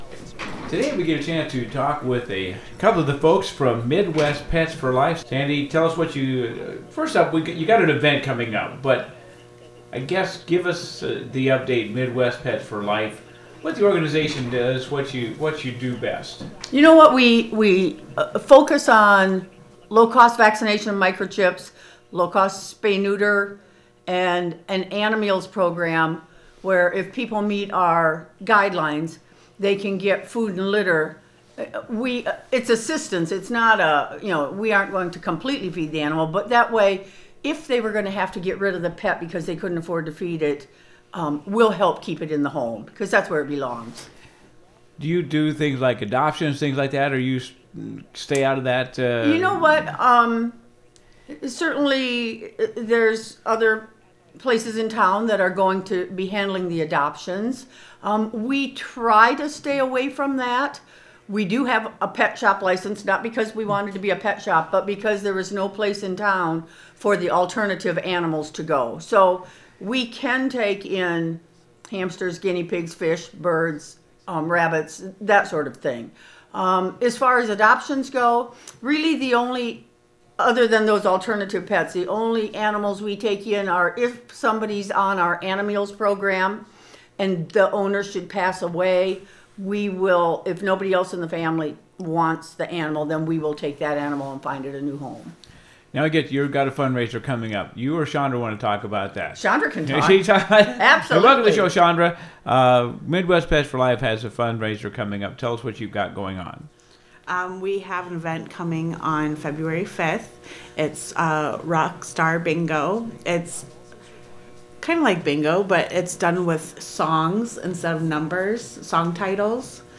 Learn more about Midwest Pets For Life in this conversation